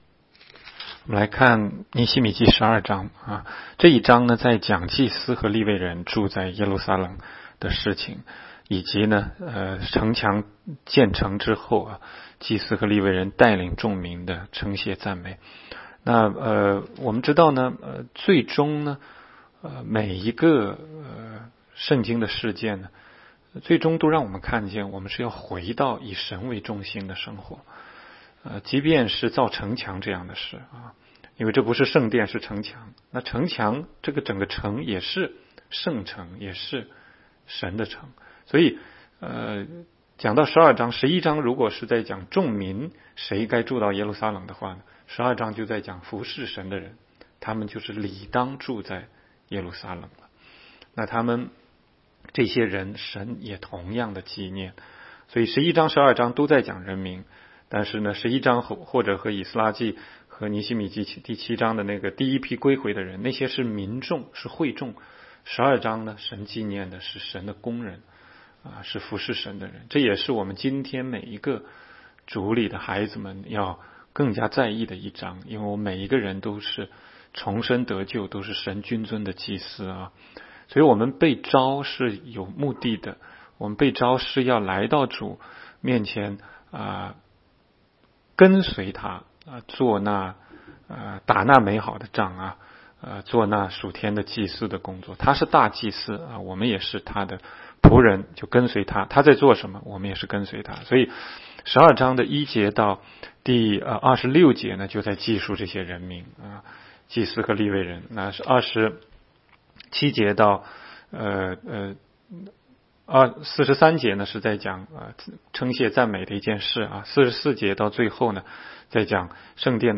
16街讲道录音 - 每日读经-《尼希米记》12章